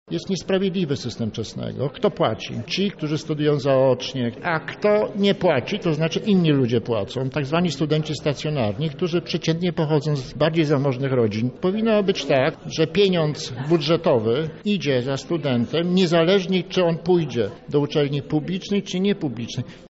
Należy zmienić sposób finansowania uczelni – stwierdza prof. Leszek Balcerowicz, były wicepremier i minister finansów.
Profesor Balcerowicz uczestniczył w inauguracji roku akademickiego w Wyższej Szkole Przedsiębiorczości i Administracji w Lublinie.